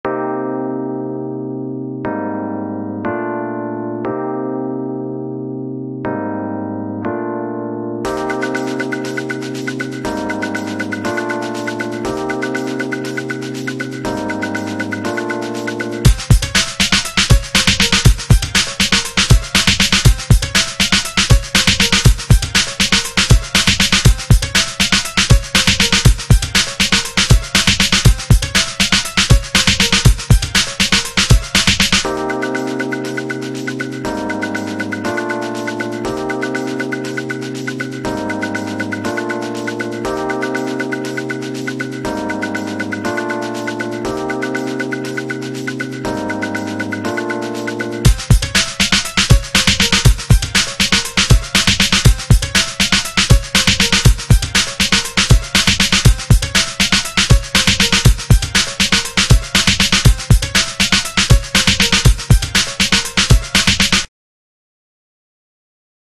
Using the built in loops and the music editor, you can create music really quickly.
The ‘tracks’ are not complete, usually end abruptly, are often simply an idea that led nowhere and I got bored of — usually for good reason 🙂